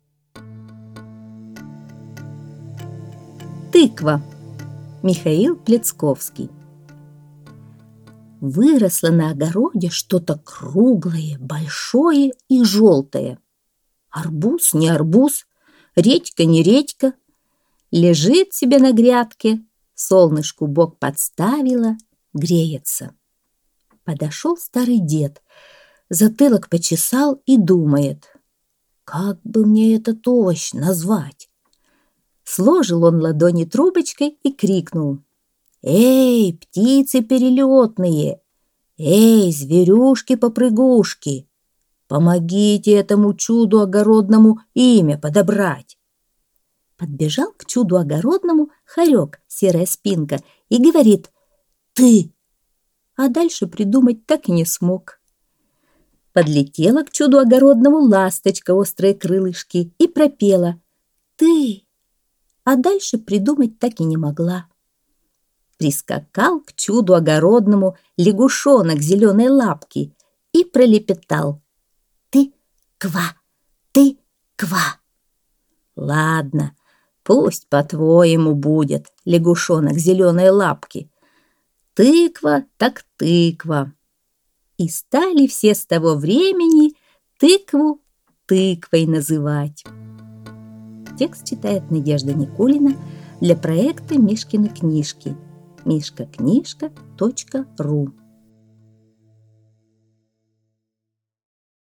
Аудиосказка «Тыква»